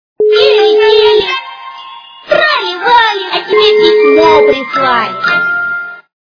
» Звуки » звуки для СМС » Детский голос - Тили-тили, трали-вали, а тебе письмо прислали...
При прослушивании Детский голос - Тили-тили, трали-вали, а тебе письмо прислали... качество понижено и присутствуют гудки.